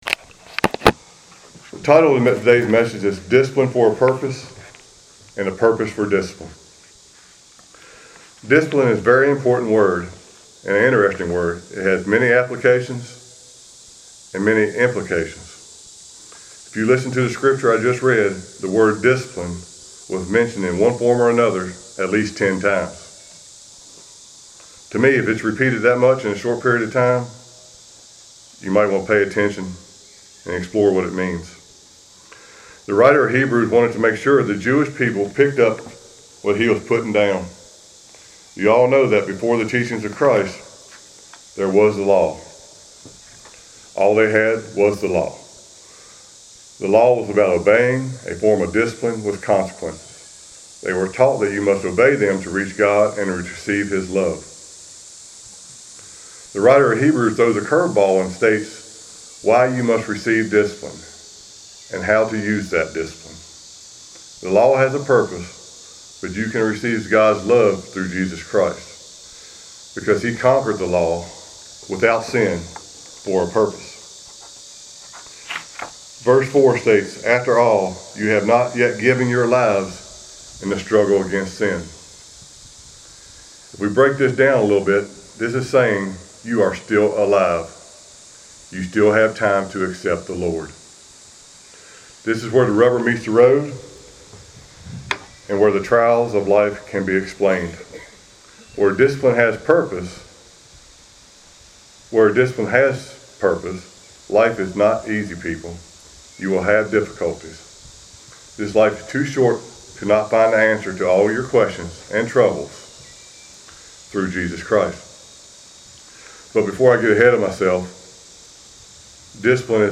Passage: Hebrews 12:4-11 Service Type: Sunday Worship